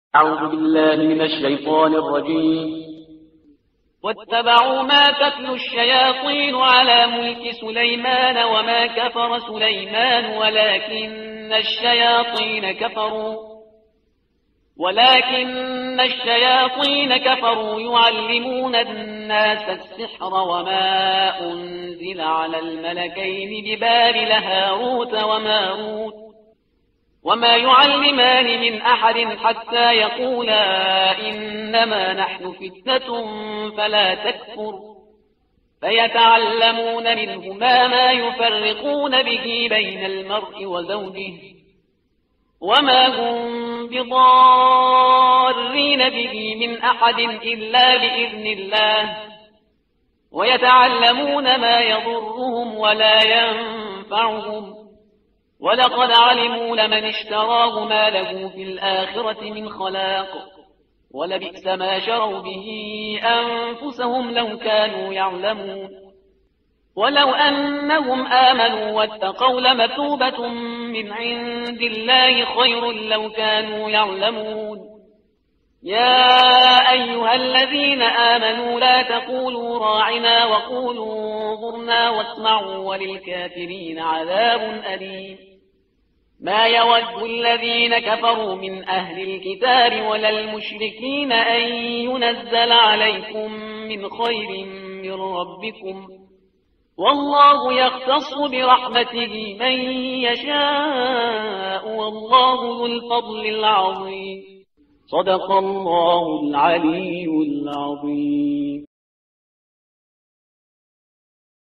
ترتیل صفحه 16 قرآن با صدای شهریار پرهیزگار